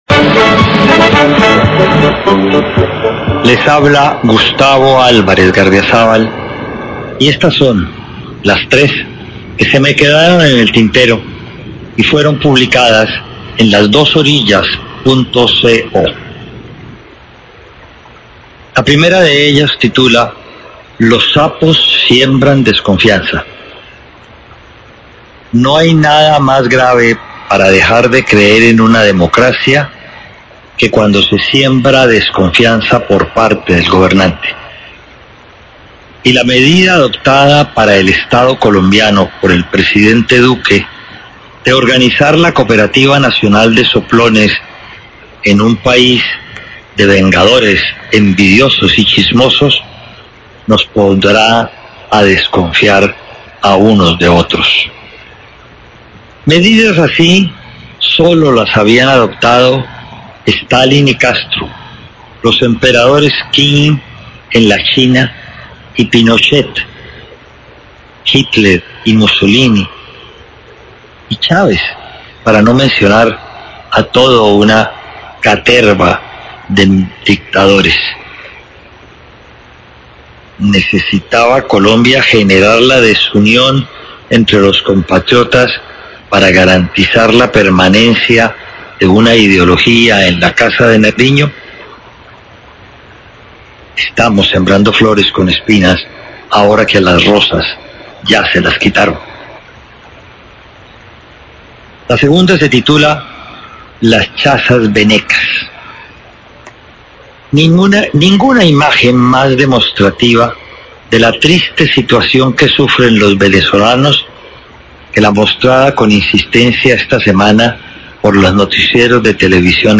Radio
opinión